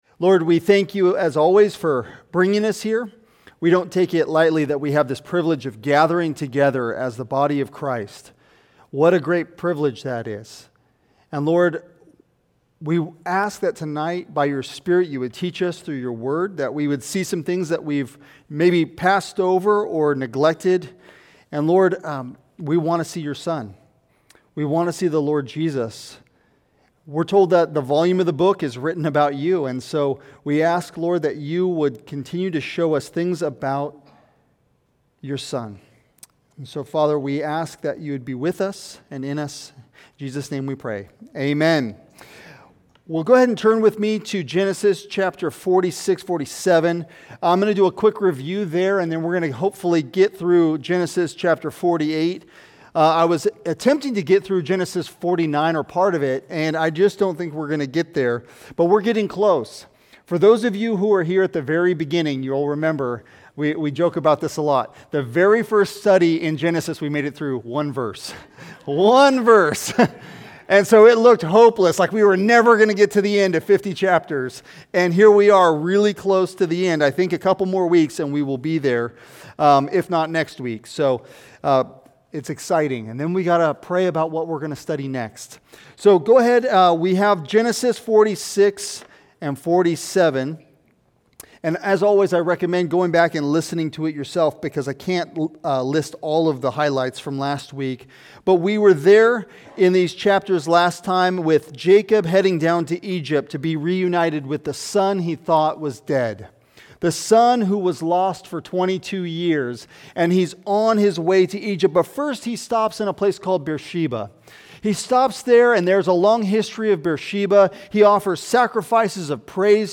Sermons | Heritage Bible Church of Tri Cities